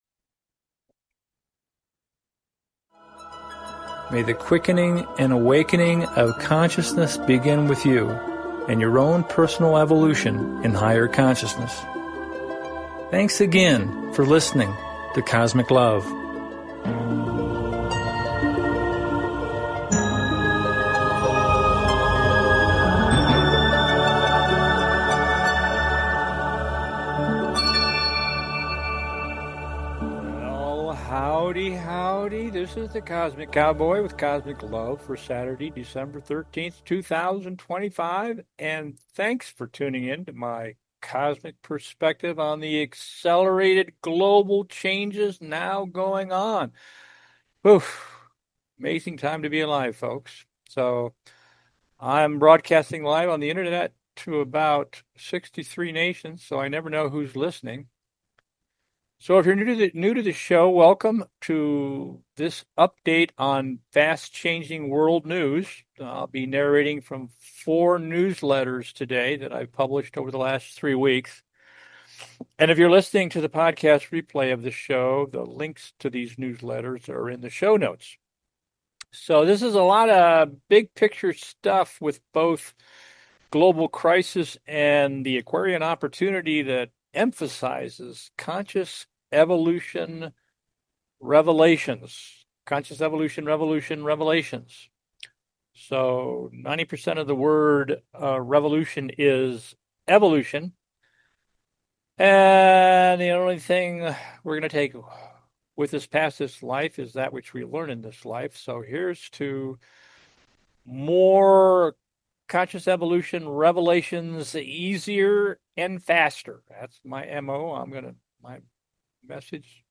Narrating from 4 recent newsletters: